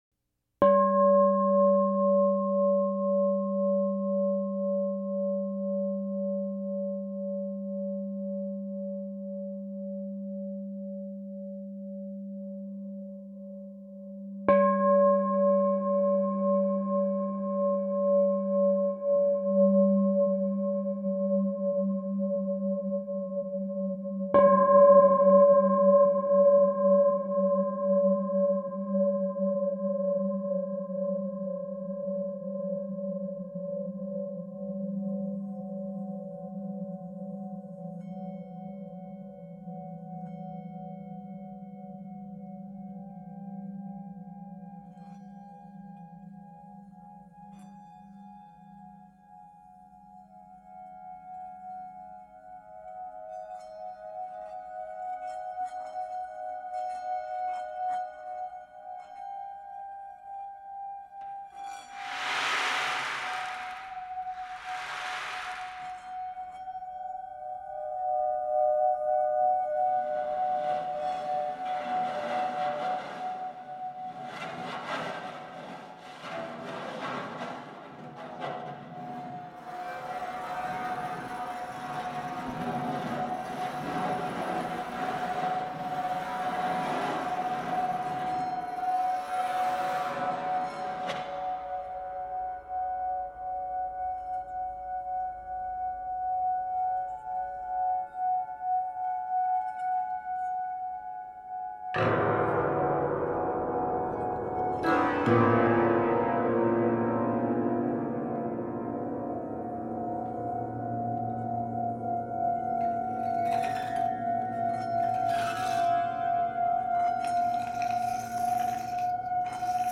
Ogg Vorbis MP3 Fête 2008 Musique Concrète How many sounds can you get from a baloon?